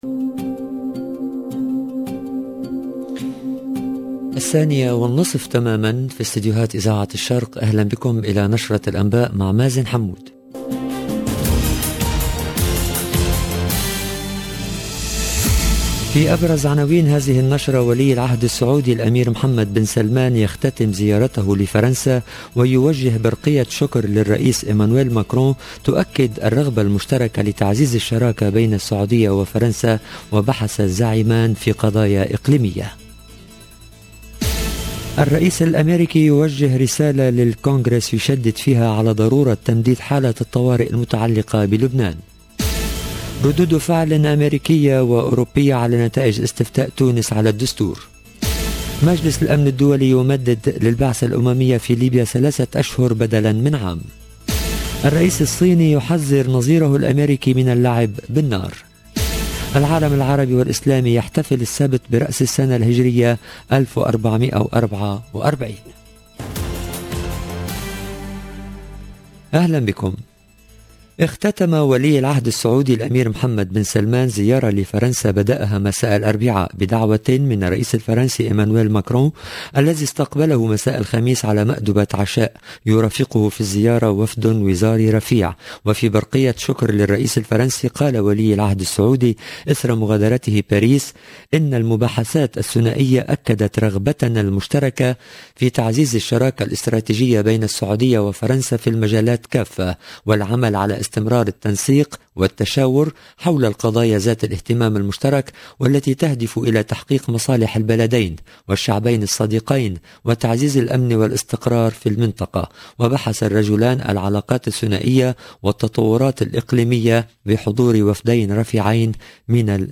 LE JOURNAL EN LANGUE ARABE DE LA MI-JOURNEE DU 29/07/22